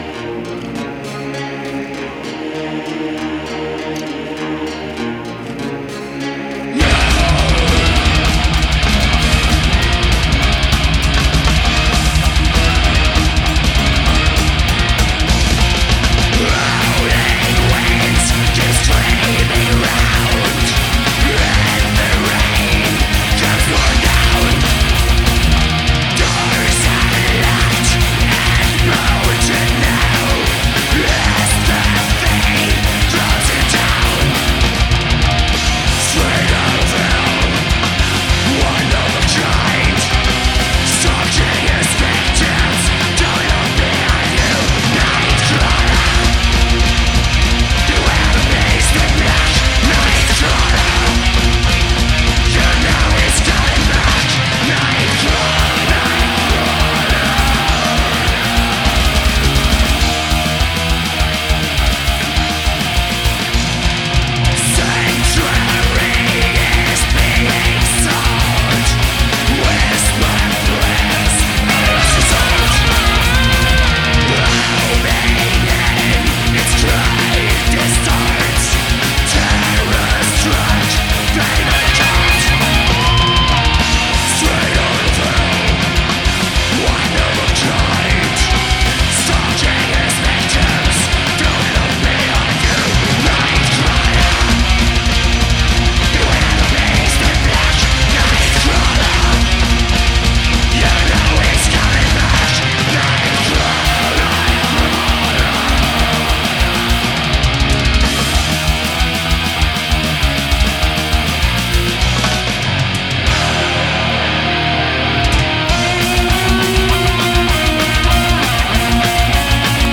Black Metal